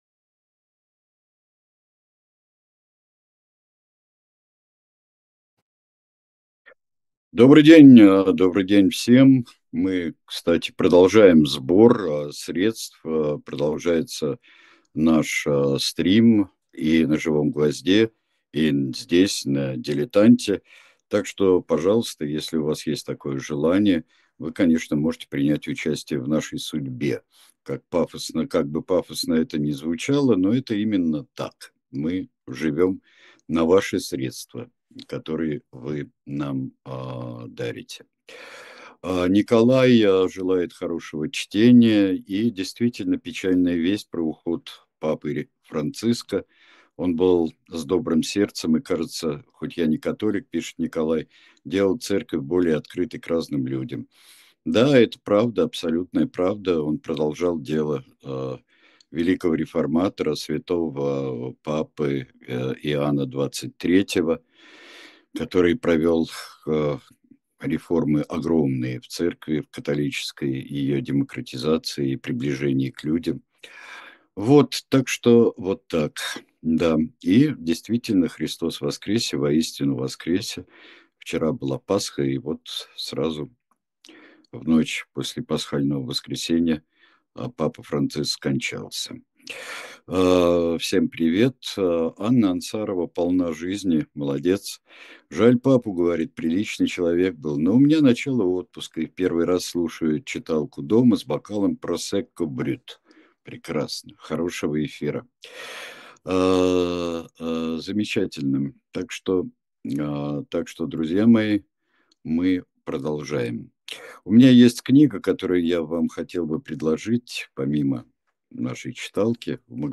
Читает произведение Сергей Бунтман